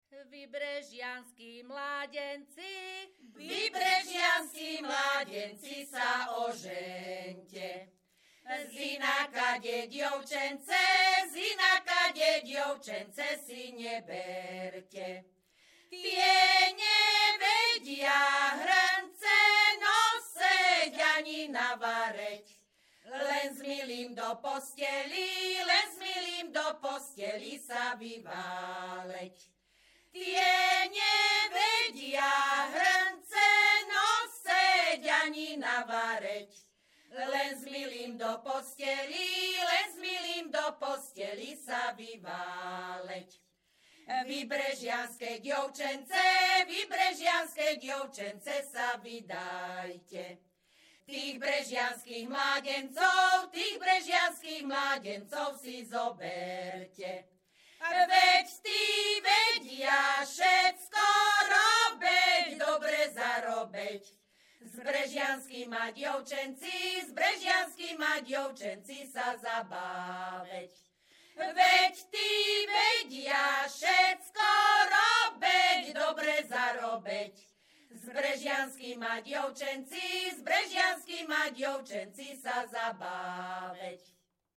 Descripton ženský skupinový spev bez hudobného sprievodu
Performers Spevácka skupina Hronka z Brehov
Place of capture Brehy
Key words ľudová pieseň